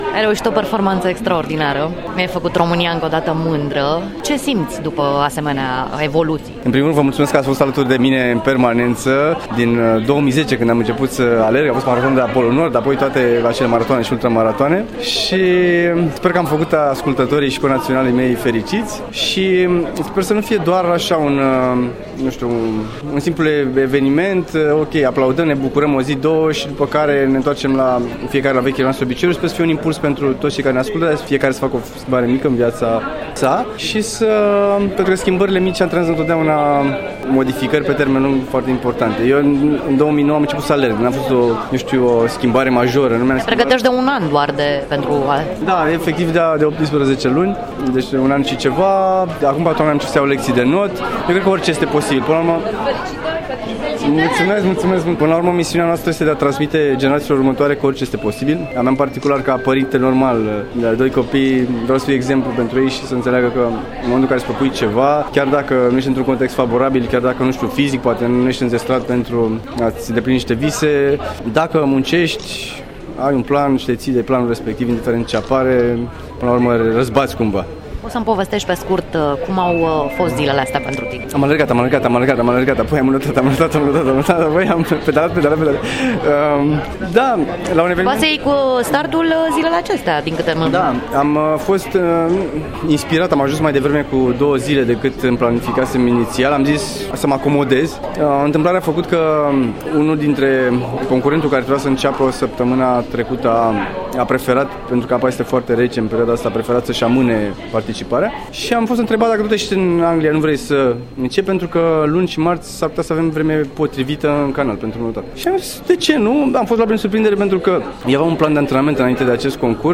Un interviu emotionant
Un reportaj